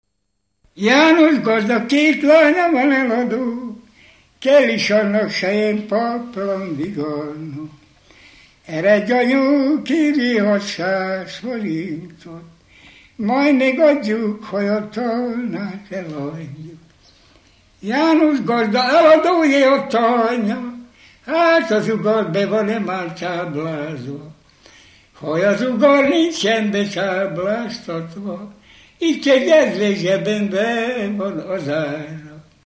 Alföld - Csongrád vm. - Szentes
ének
Dallamtípus: Dudanóta (11 szótagos) 2
Stílus: 6. Duda-kanász mulattató stílus
Kadencia: 5 (1) 2 1